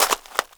GRAVEL 4.WAV